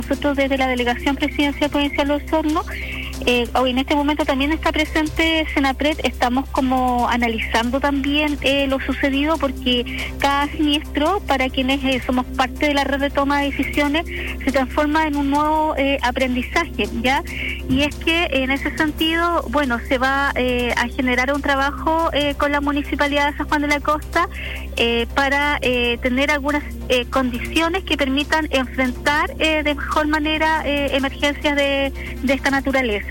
A propósito del incendio forestal ocurrido en la localidad de Pucatrihue, La Radio conversó con la delegada presidencial provincial de Osorno, Claudia Pailalef, quien comentó el análisis de la emergencia.